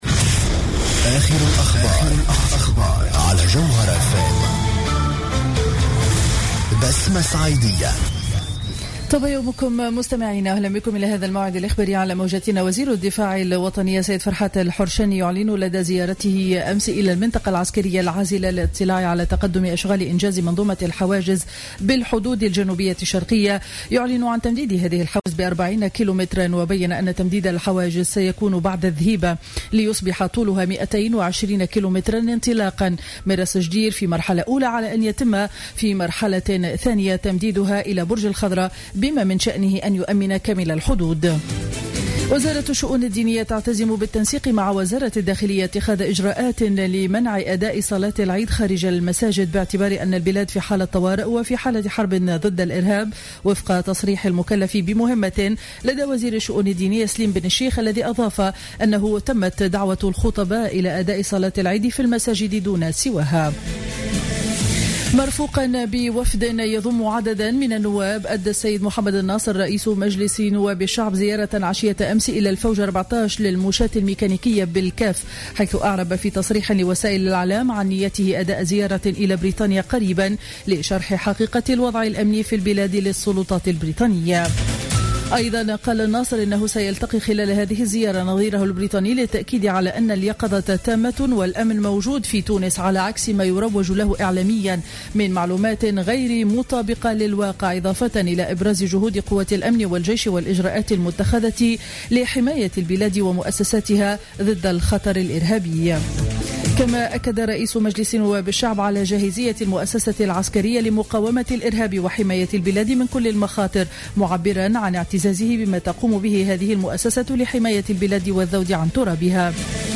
نشرة أخبار السابعة صباحا ليوم الأحد 12 جويلية 2015